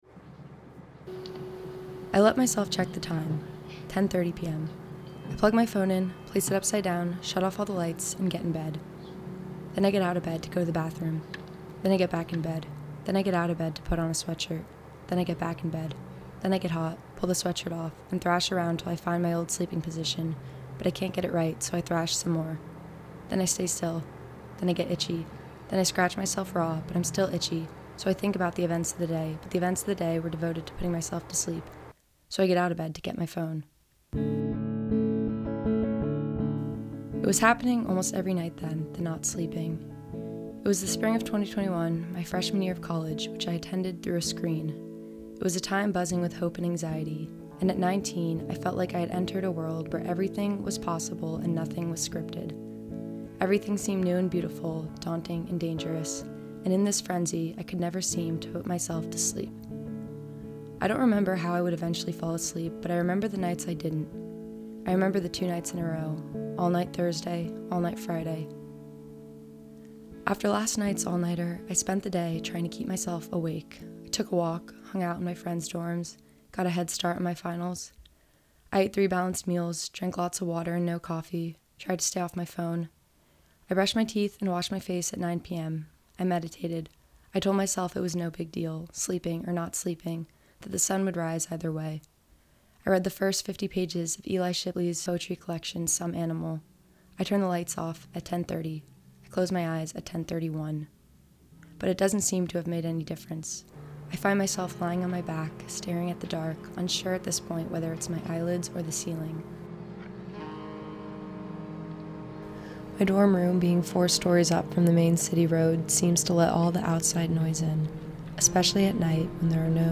Audio Narrative